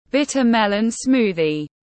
Sinh tố mướp đắng tiếng anh gọi là bitter melon smoothie, phiên âm tiếng anh đọc là /’bitə ‘melən ˈsmuː.ði/
Bitter melon smoothie /’bitə ‘melən ˈsmuː.ði/